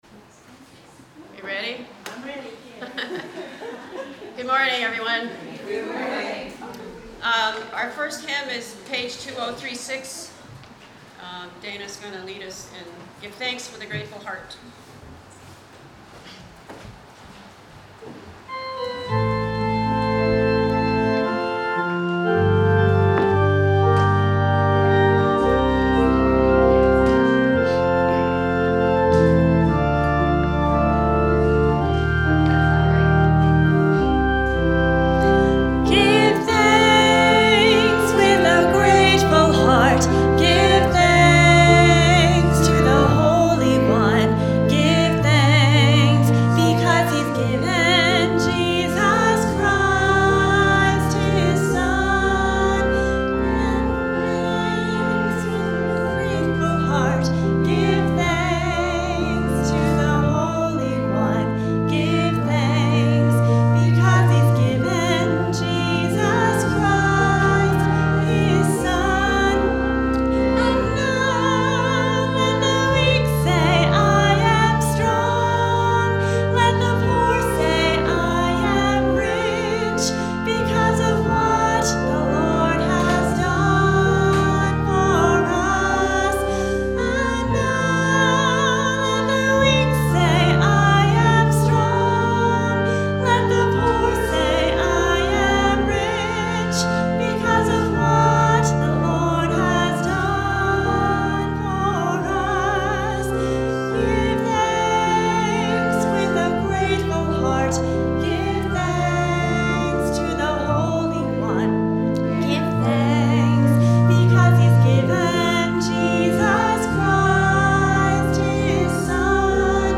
You are welcome to join us for in-person worship in the Essex Center UMC sanctuary, or you may participate on Facebook Live by clickingRead more